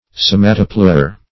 Search Result for " somatopleure" : The Collaborative International Dictionary of English v.0.48: Somatopleure \So"ma*to*pleure\, n. [Gr. sw^ma, sw`matos, body + pleyra` side.]
somatopleure.mp3